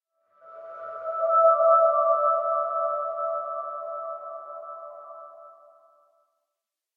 Minecraft Version Minecraft Version snapshot Latest Release | Latest Snapshot snapshot / assets / minecraft / sounds / ambient / cave / cave2.ogg Compare With Compare With Latest Release | Latest Snapshot
cave2.ogg